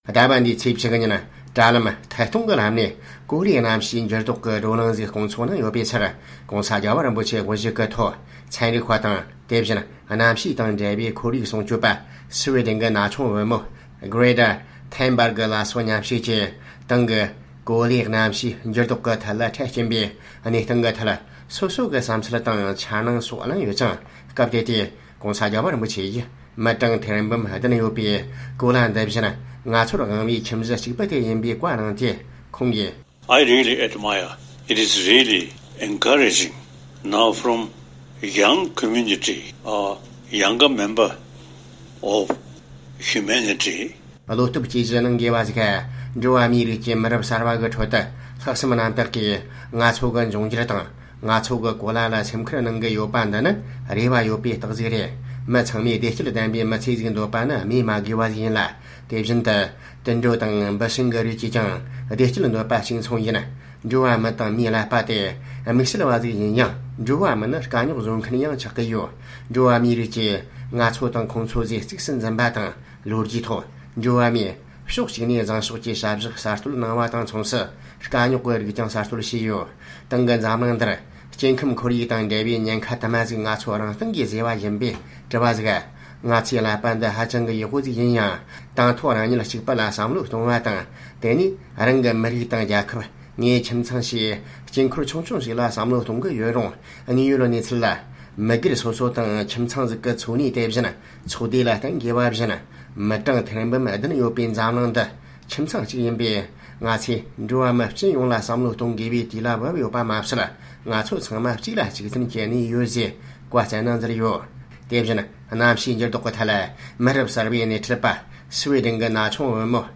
༧གོང་ས་རྒྱལ་བ་རིན་པོ་ཆེ་དབུ་བཞུགས་ཀྱིས་ཁོར་ཡུག་སྲུང་སྐྱོབ་དང་ཚན་རིག་པ་བཅས་ནས་བགྲོ་གླེང་གནང་བ